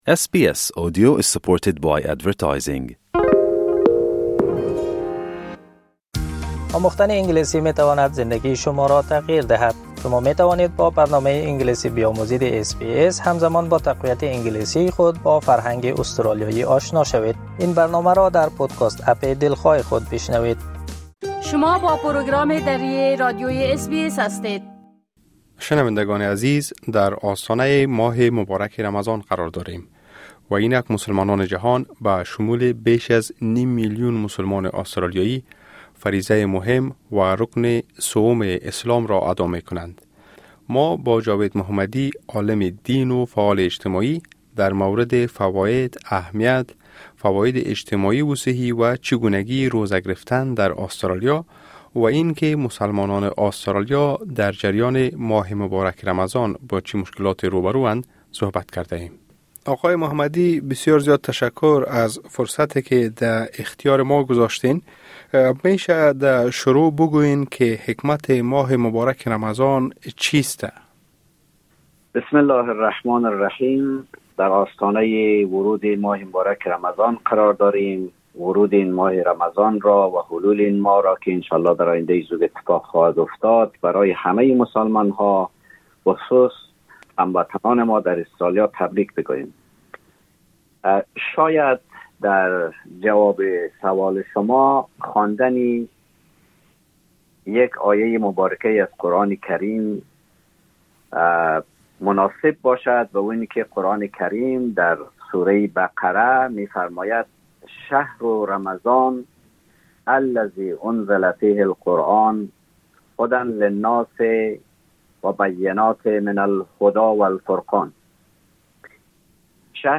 روزه‌ داری در دنیای پرچالش؛ گفتگوی ویژه درباره فضایل و چالش‌های ماه رمضان